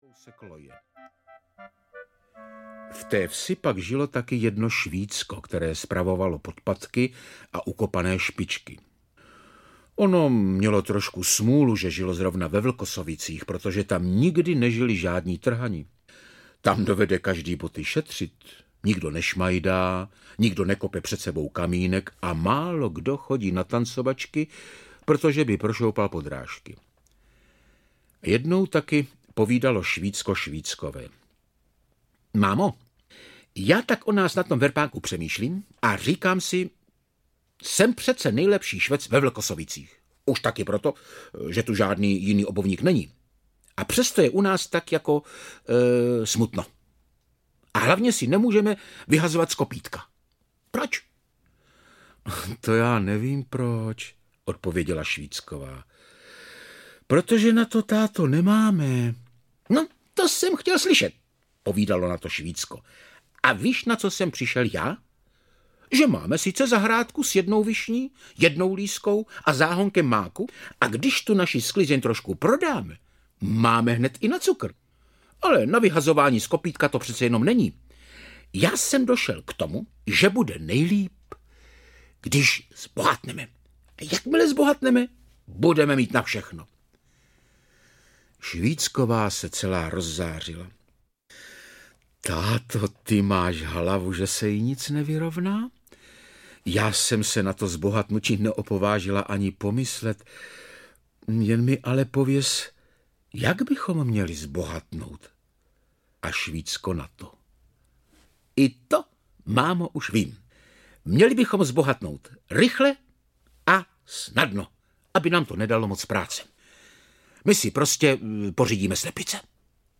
Pohádková lampička audiokniha
pohadkova-lampicka-audiokniha